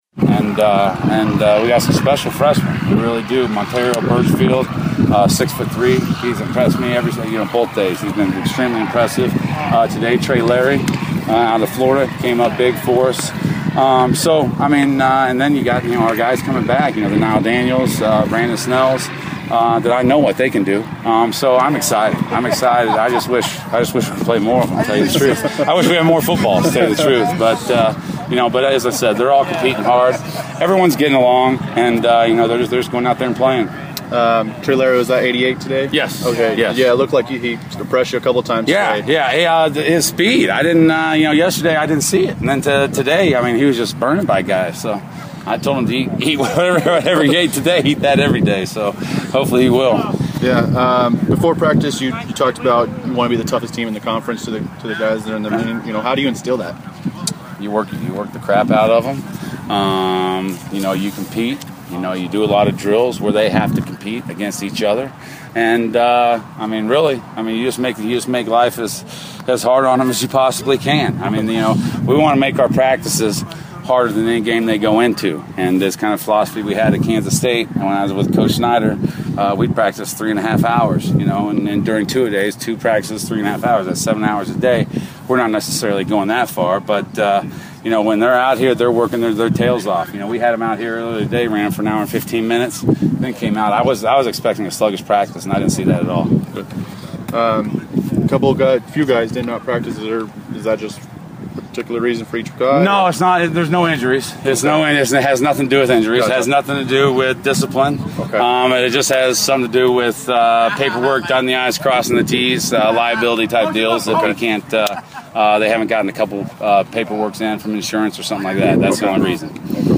Day Two Practice Interviews